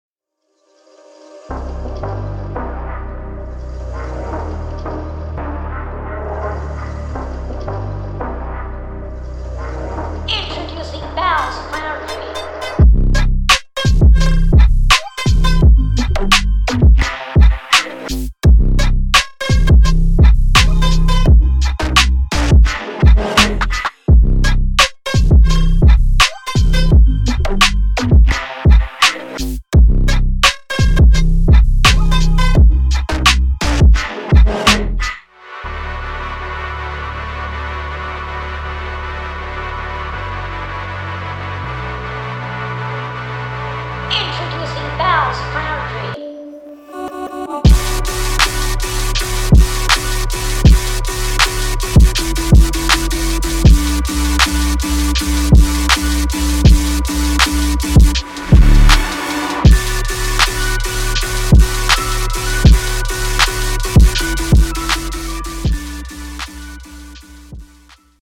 Niche